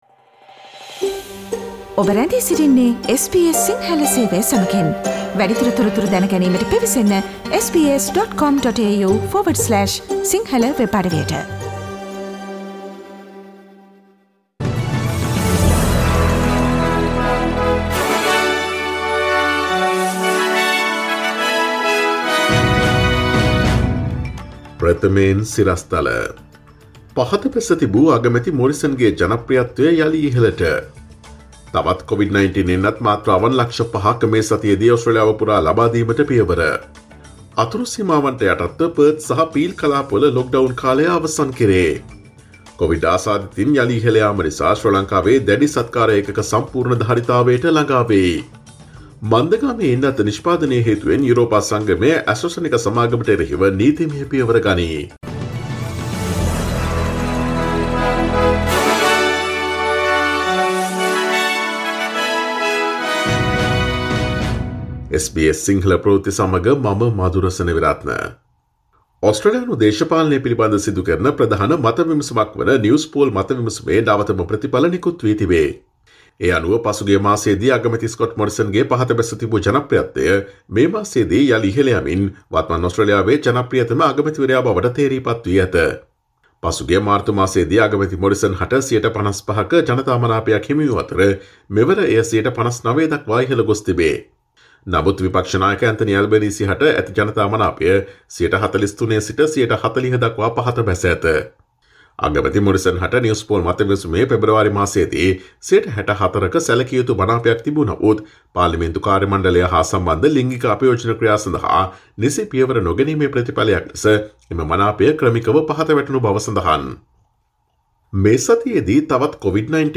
ඔස්ට්‍රේලියාවේ, ශ්‍රී ලංකාවේ, ජාත්‍යන්තරයේ සහ ක්‍රීඩා පිටියේ උණුසුම් පුවත් රැගත් SBS සිංහල ගුවන්විදුලියේ 2021 අප්‍රේල් 27 වනදා අඟහරුවාදා වැඩසටහනේ ප්‍රවෘත්ති ප්‍රකාශයට සවන් දෙන්න.